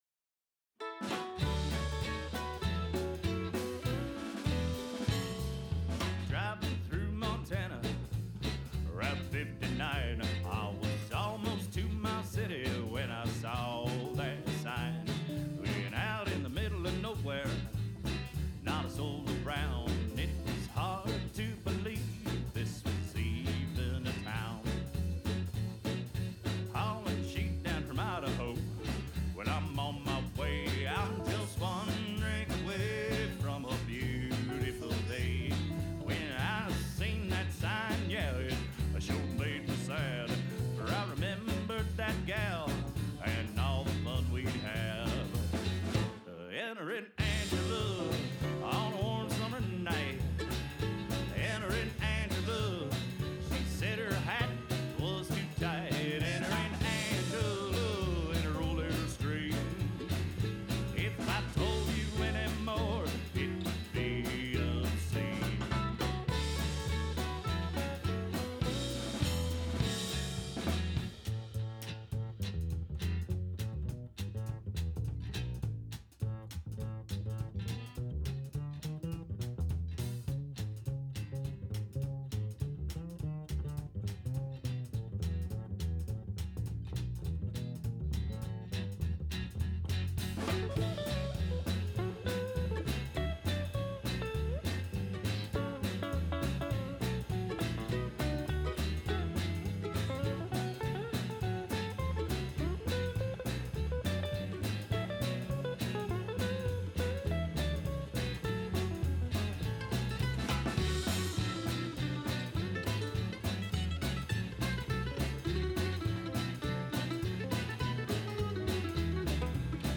honky-tonk, Western swing band